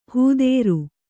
♪ hūdēru